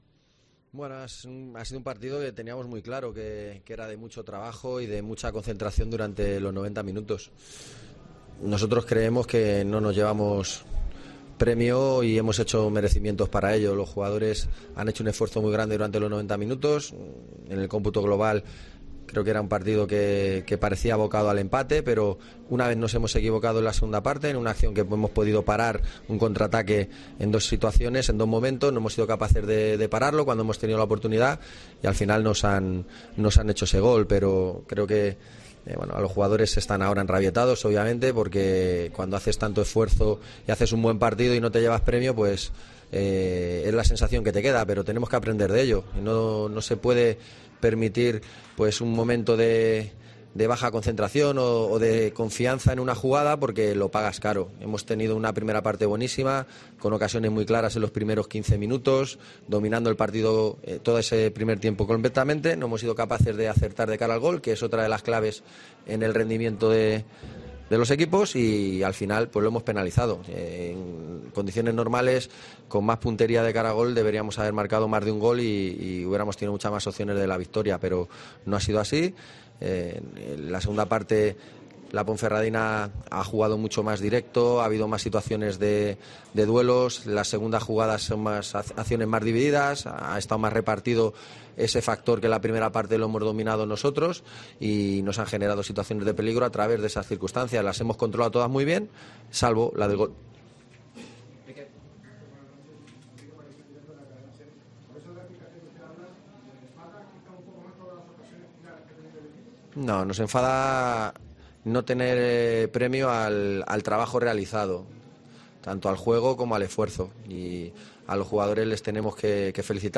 POSTPARTIDO
Escucha aquí las palabras del míster del Málaga, Víctor Sánchez del Amo, tras perder 1-0 ante la Ponferradina en El Toralín